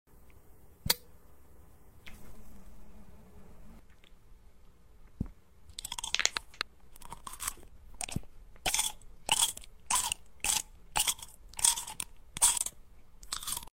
Tung Tung Tung Sahur ASMR. sound effects free download